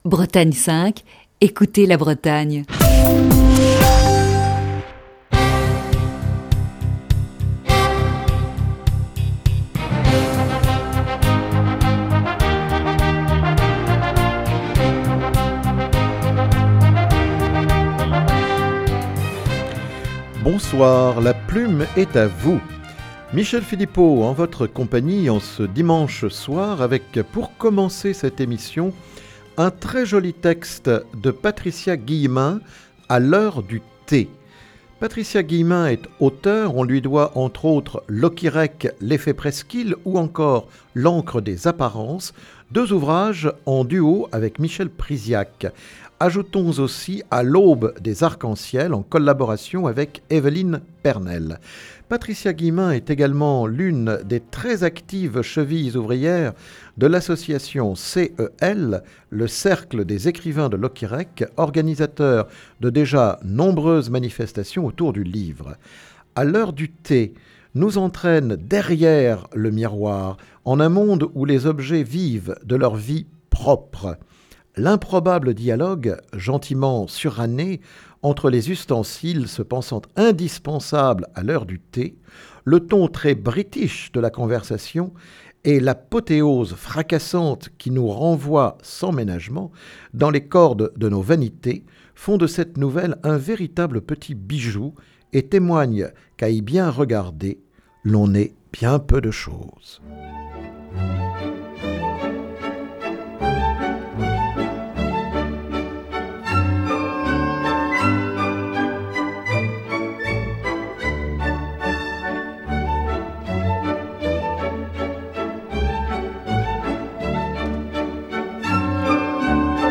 (Émission diffusée initialement le 10 novembre 2019).